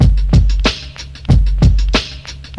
Drum Loop (Go With The Flow).wav